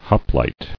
[hop·lite]